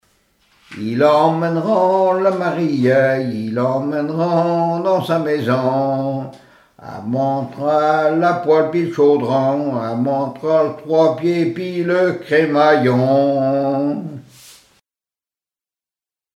Genre brève
témoignages sur le mariage et chansons de circonstance
Pièce musicale inédite